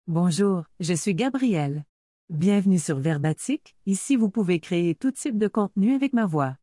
Gabrielle — Female Canadian French AI voice
Gabrielle is a female AI voice for Canadian French.
Voice sample
Female
Gabrielle delivers clear pronunciation with authentic Canadian French intonation, making your content sound professionally produced.